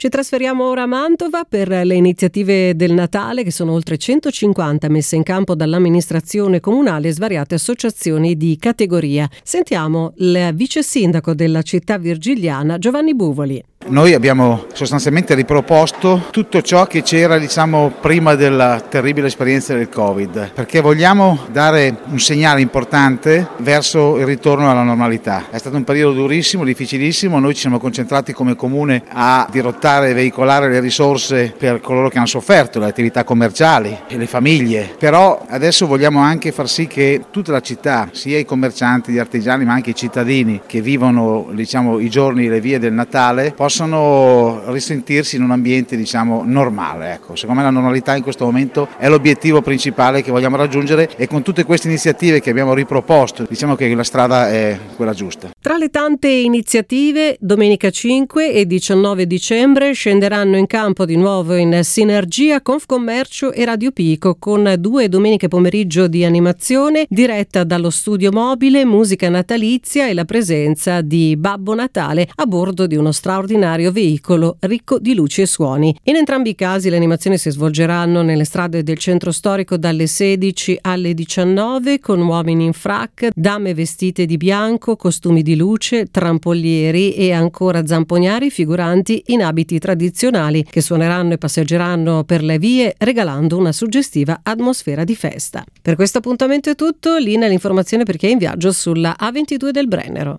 3/12/2021: Sono oltre 150 le iniziative proposte per il Natale a Mantova. Al nostro microfono il Vice Sindaco della città Giovanni Buvoli